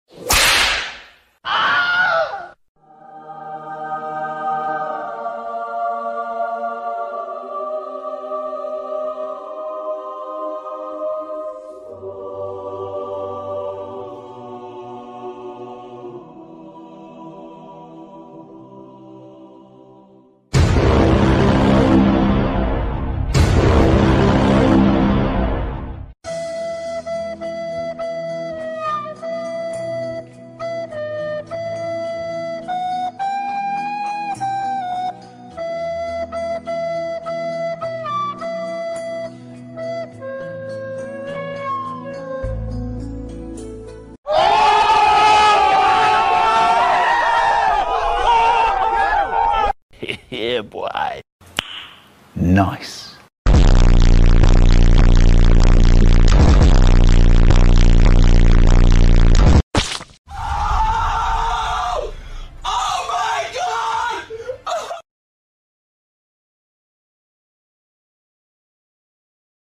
10+ Free Sound Effects You
From funny meme sounds to cinematic whooshes and impact hits, it’s all here and ready for download.